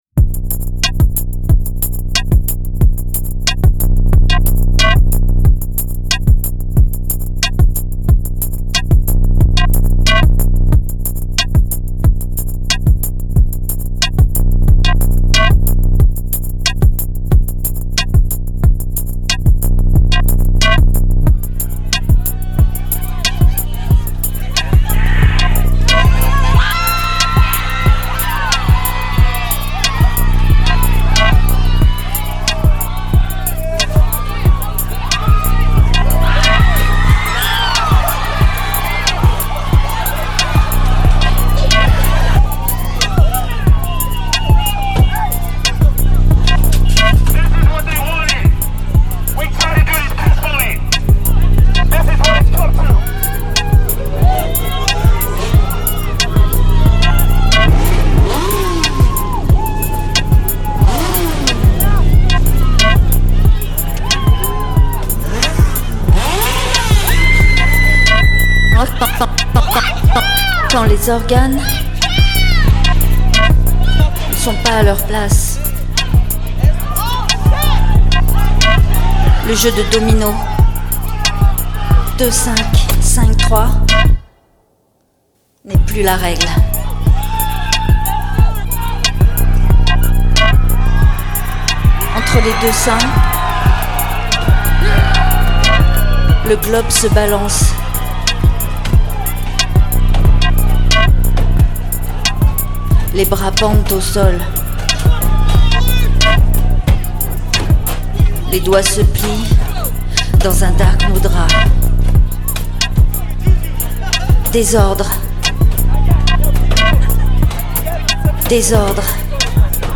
acousmatic composition for a minimum of 8 loudspeakers
an electronic live performance